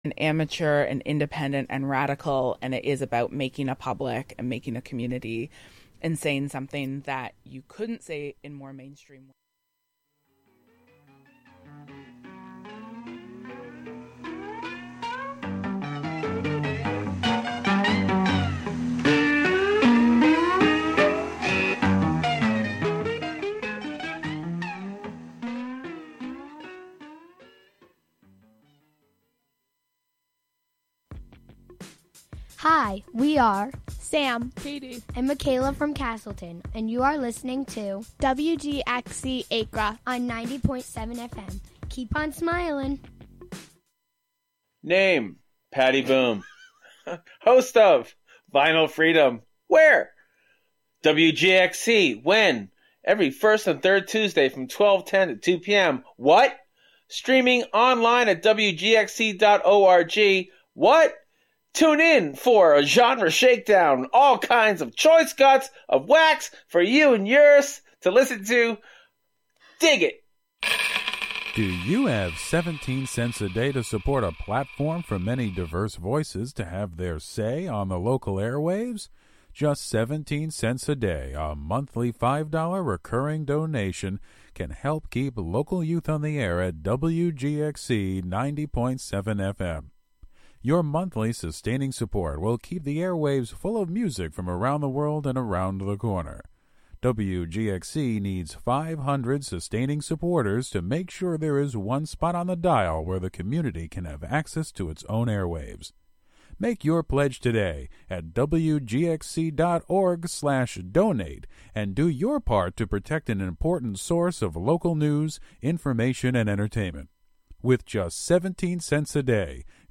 The show honors the necessity to centralize music and sound in decolonizing and indigenizing culture. It also amplifies Indigenous music traditions to bring attention to their right to a sustainable future in the face of continued violence and oppression. We will explore the intersection of acoustic ecology, musical ethnography, soundscape studies, and restorative listening practices from the perspective of Indigenous musical TEK (Traditional Ecological Knowledge).